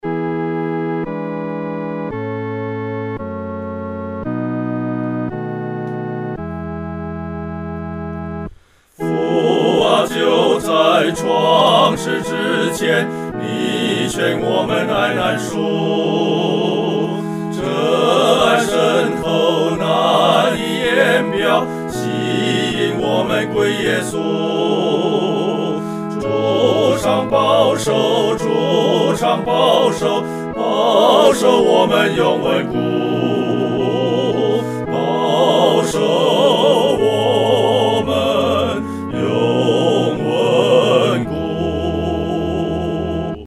合唱（四声部）
赞父奇爱-合唱（四声部）.mp3